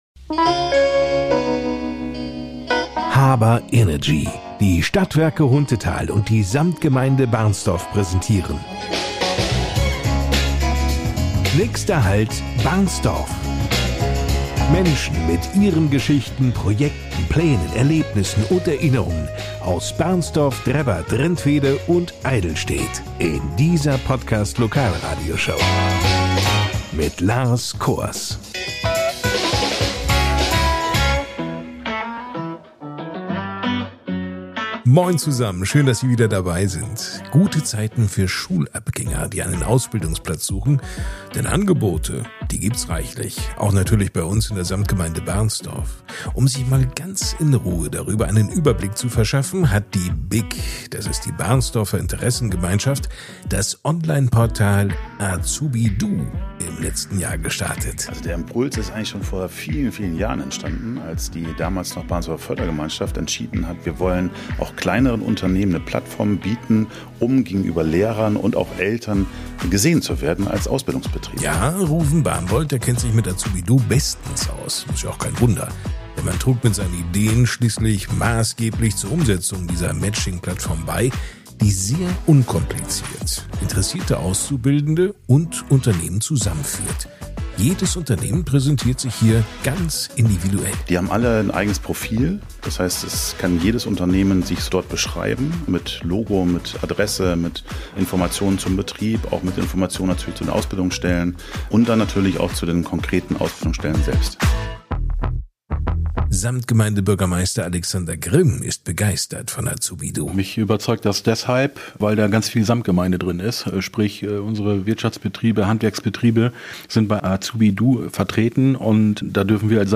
Die Podcast-Lokalradioshow